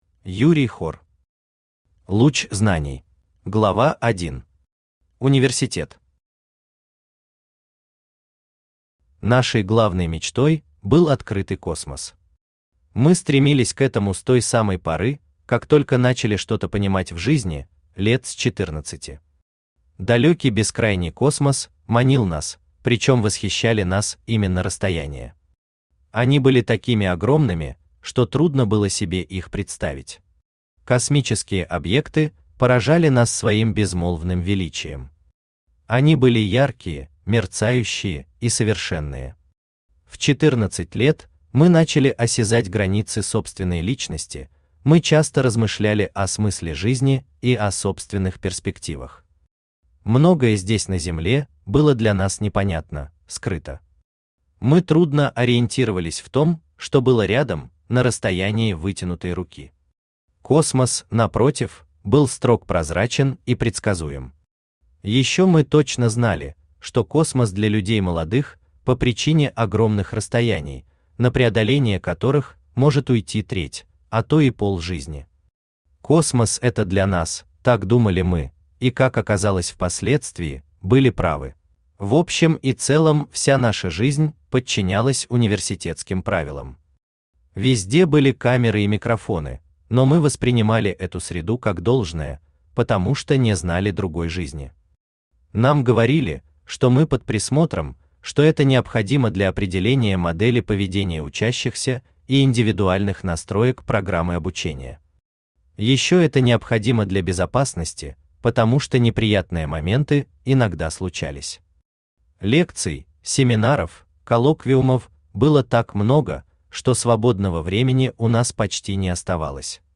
Аудиокнига Луч знаний | Библиотека аудиокниг
Aудиокнига Луч знаний Автор Юрий Хор Читает аудиокнигу Авточтец ЛитРес.